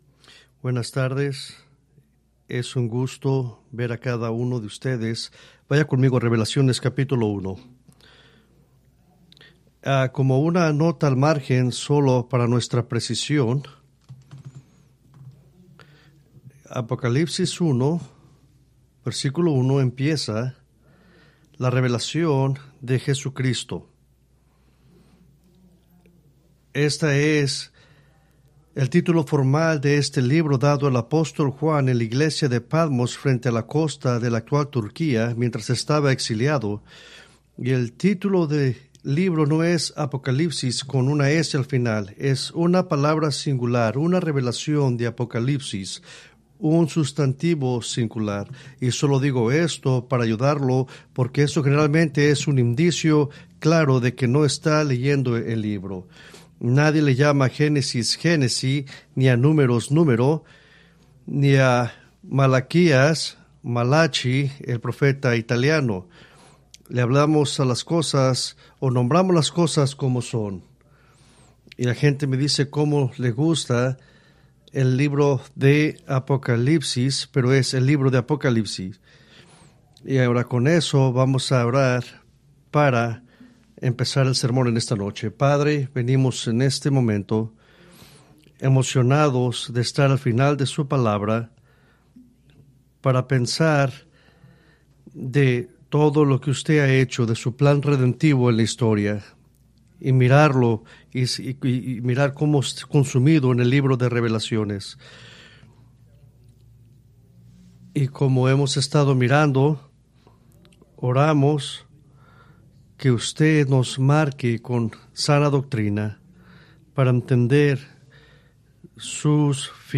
Preached January 19, 2025 from Escrituras seleccionadas